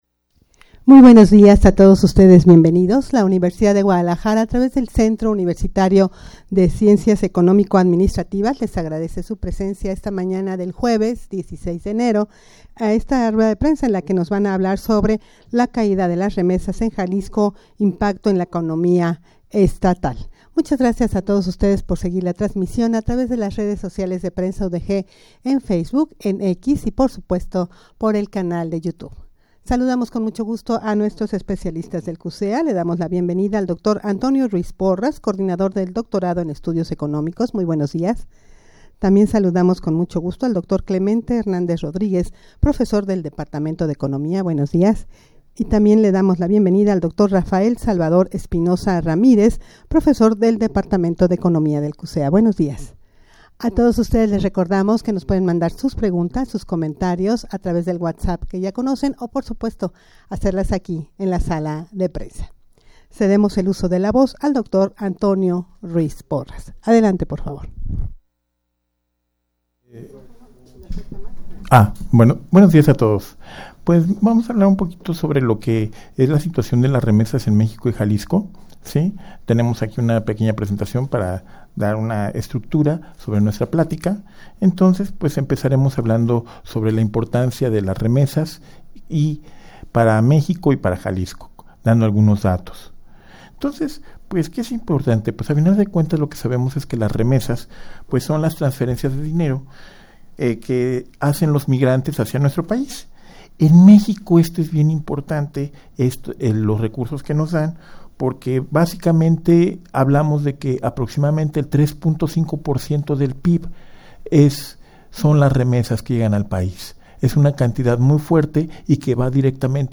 Audio de la Rueda de Prensa
rueda-de-prensa-caida-de-las-remesas-en-jalisco-impacto-en-la-economia-estatal.mp3